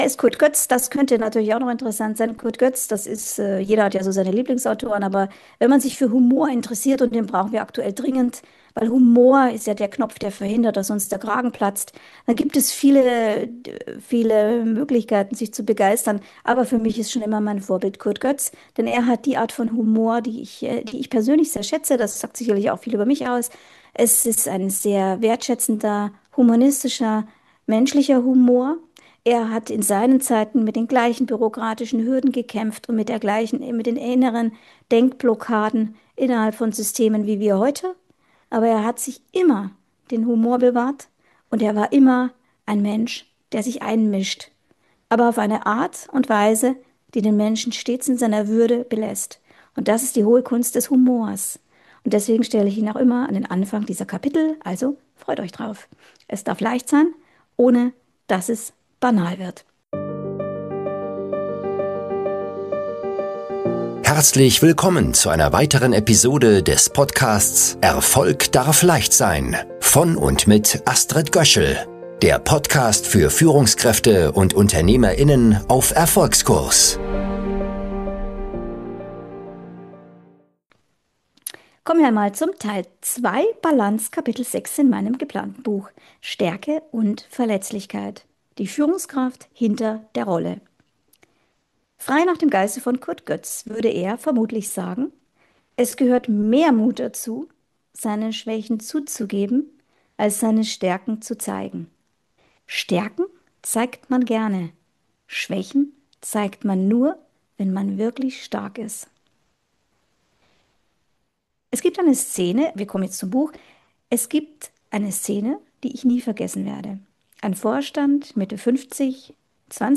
Hinweis: Der Ton ist in dieser Folge nicht optimal, weil ich die
Frequenz falsch eingestellt hatte.